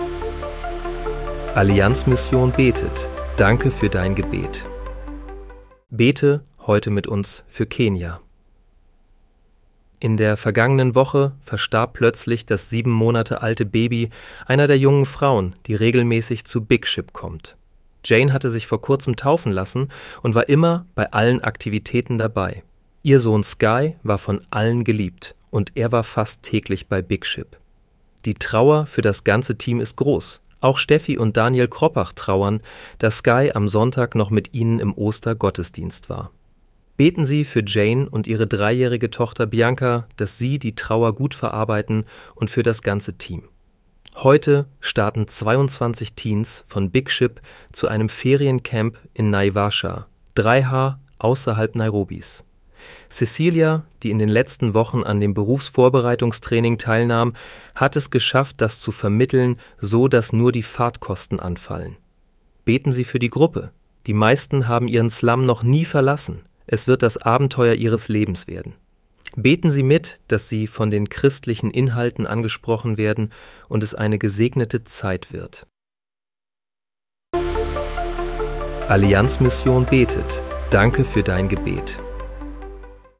Bete am 15. April 2026 mit uns für Kenia. (KI-generiert mit der